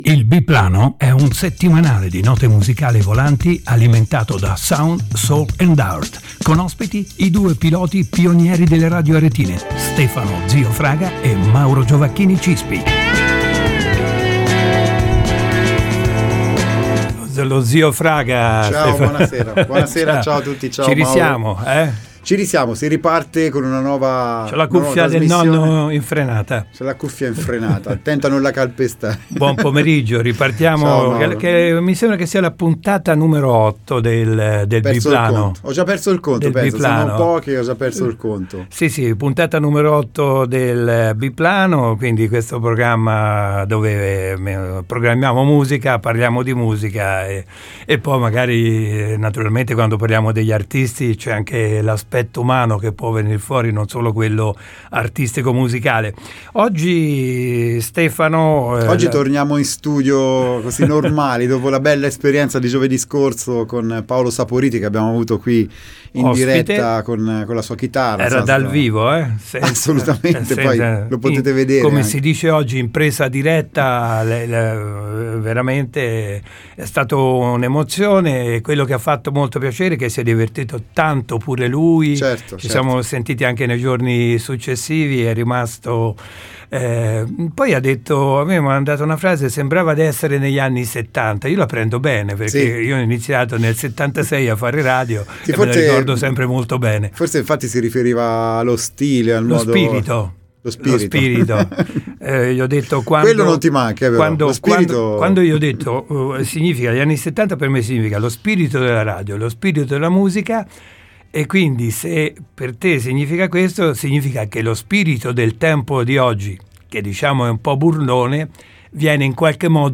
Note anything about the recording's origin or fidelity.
i migliori Album Live usciti nel 2023